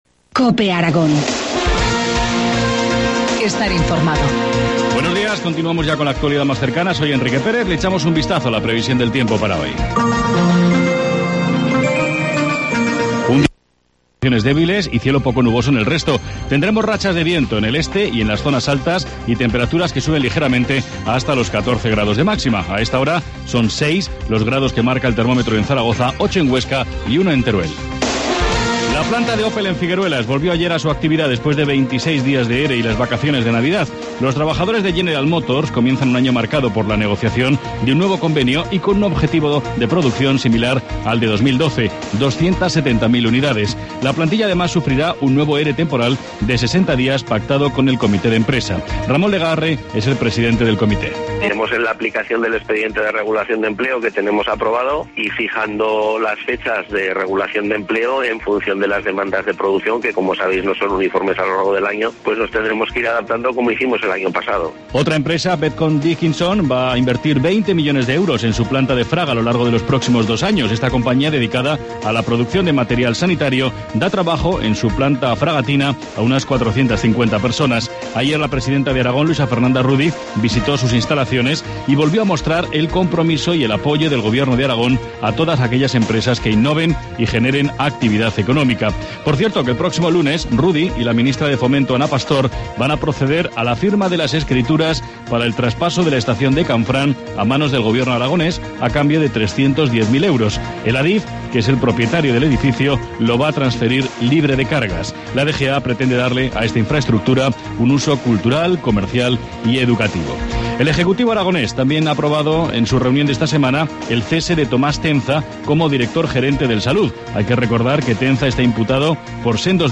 Informativo matinal, jueves 9 de enero, 8.25 horas